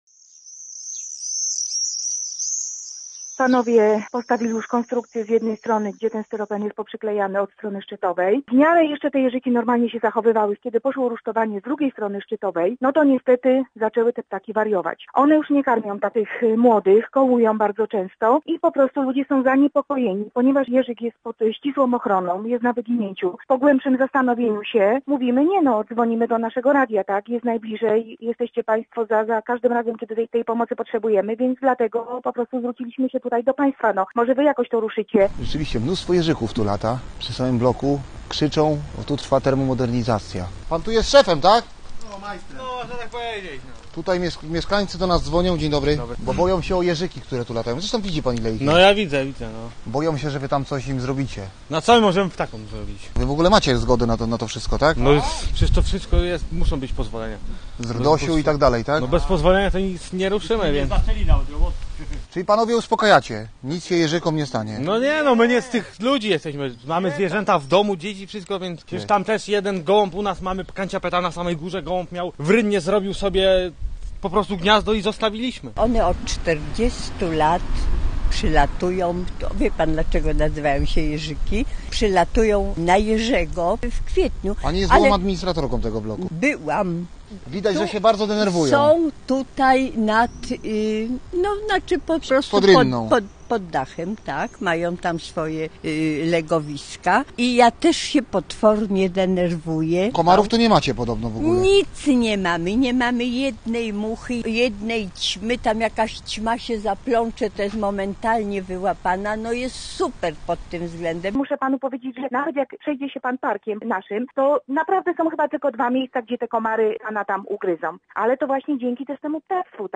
Posłuchaj relacji i dowiedz się więcej: Nazwa Plik Autor Przy remoncie bloków giną siedliska ptaków jerzyków audio (m4a) audio (oga) Administrator bloku przy ul.